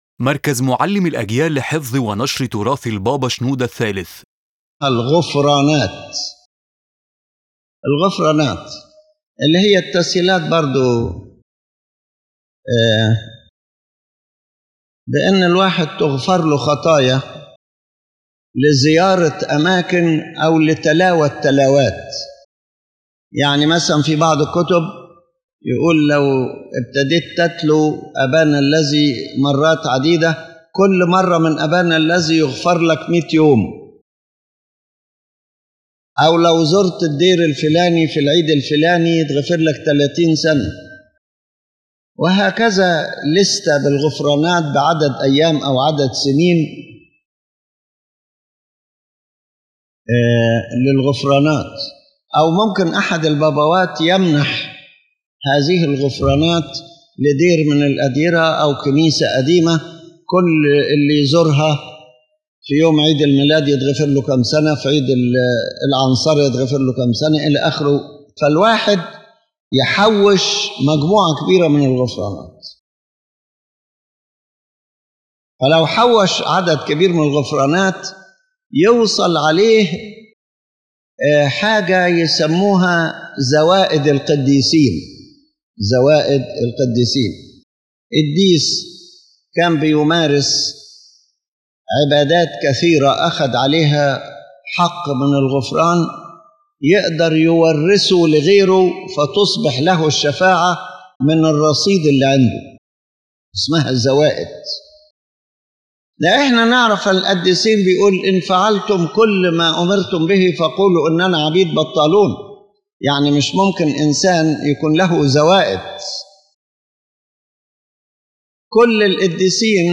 The lecture explains the concept of indulgences as presented in Catholic thought and discusses the related idea of the merits of the saints, clarifying their incompatibility with Orthodox biblical and theological teaching.
Pope Shenouda III explains that forgiveness cannot be the result of visiting places or reciting prayers a certain number of times or years, because forgiveness is a deep spiritual matter connected to true repentance.